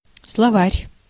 Ääntäminen
Ääntäminen : IPA: [sɫɐ.ˈvarʲ] Haettu sana löytyi näillä lähdekielillä: venäjä Käännös Ääninäyte Substantiivit 1. dictionary US UK 2. vocabulary US 3. glossary 4. lexicon US Translitterointi: slovar.